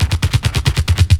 02_20_drumbreak.wav